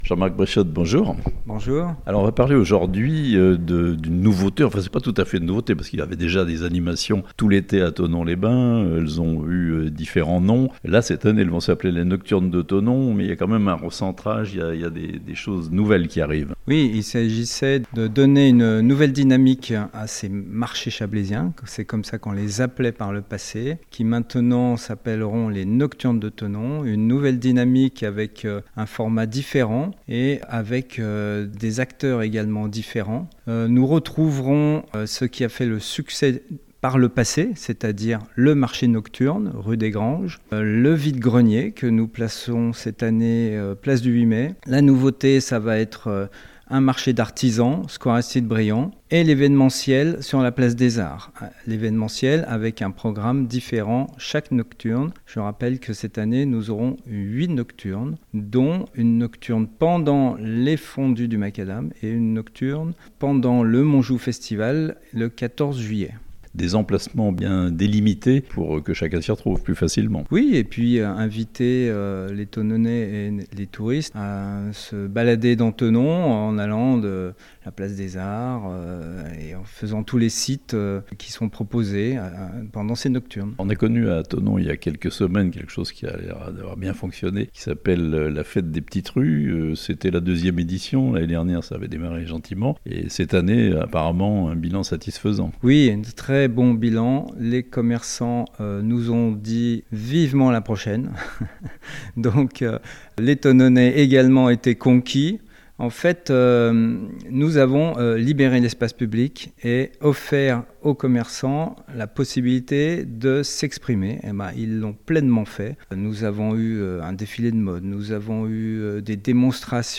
Nouveauté pour l'été : les Nocturnes de Thonon (interview)